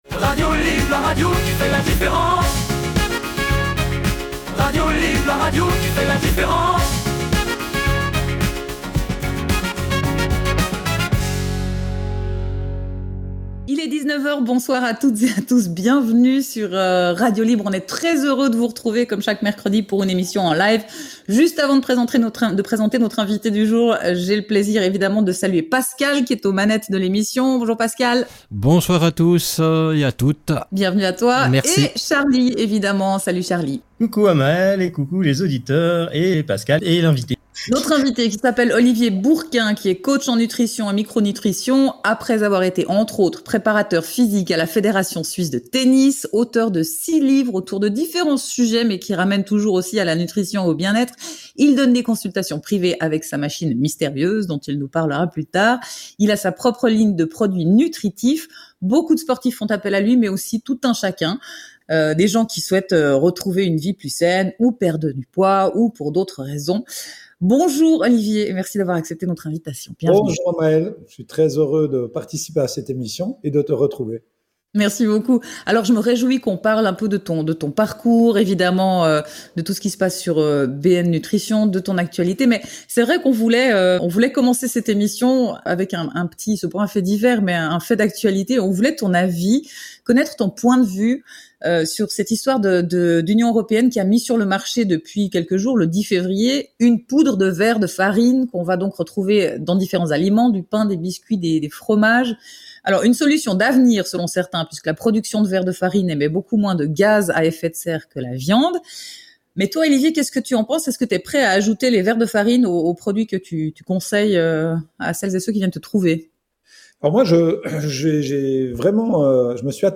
LIVE 12.02.25 - Radio Libre Suisse